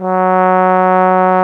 Index of /90_sSampleCDs/AKAI S-Series CD-ROM Sound Library VOL-2/1095 TROMBON